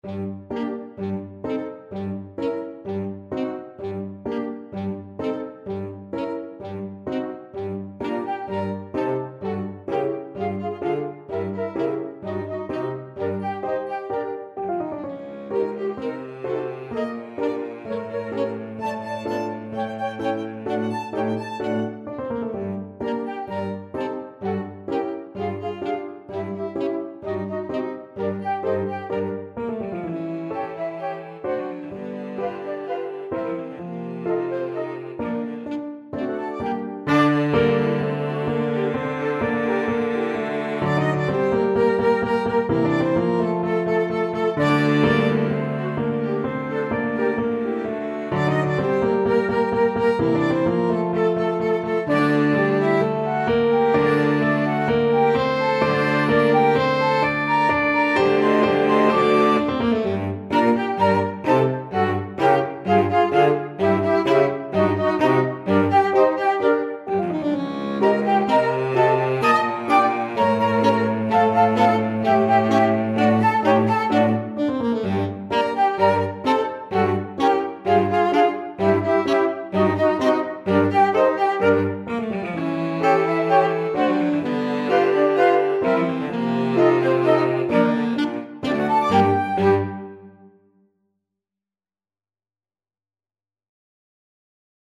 Free Sheet music for Flexible Mixed Ensemble - 4 Players
Alto SaxophoneTromboneClarinetViolin
CelloTubaClarinet
4/4 (View more 4/4 Music)
G minor (Sounding Pitch) (View more G minor Music for Flexible Mixed Ensemble - 4 Players )
Classical (View more Classical Flexible Mixed Ensemble - 4 Players Music)